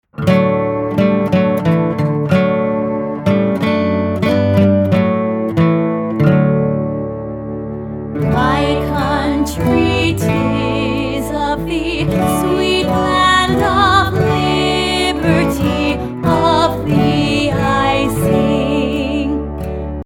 Downloadable Musical Play with Album Sheet Music.